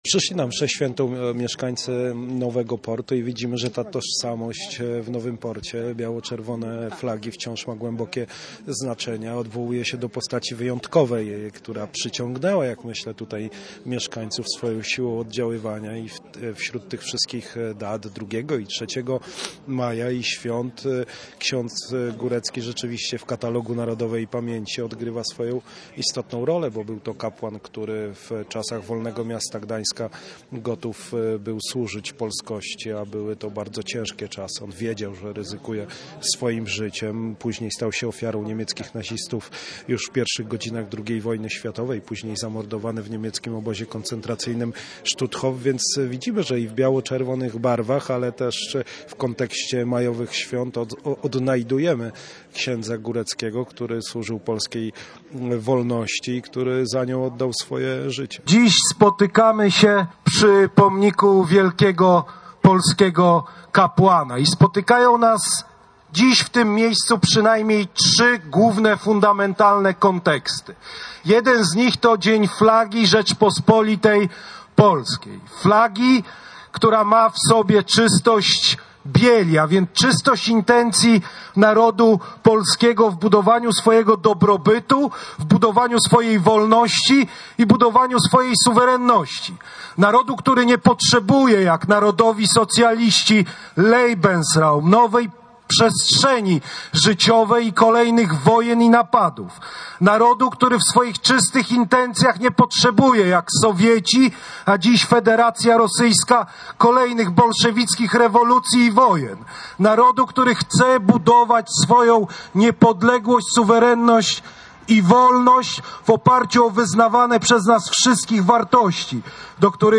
Potem uczestnicy przemaszerowali ulicami Nowego Portu na skwer księdza Góreckiego.